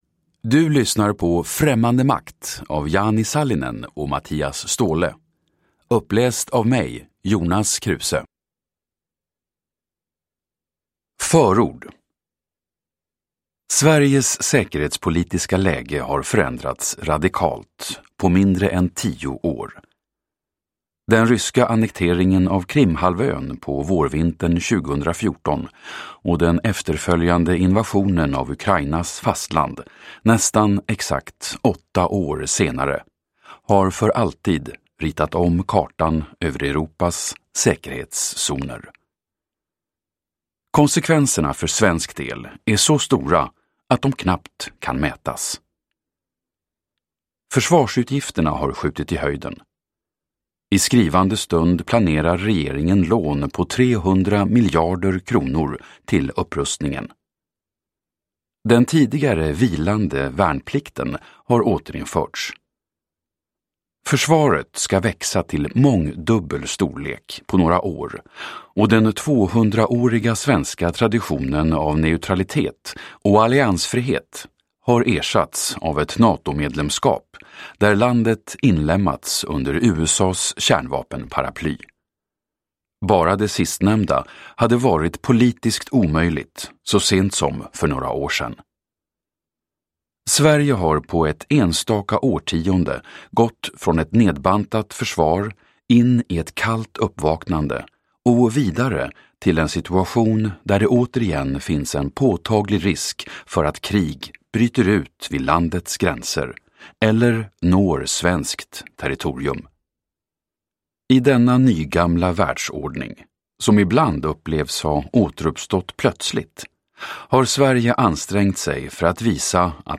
Främmande makt : Utländska spioner på svensk mark (ljudbok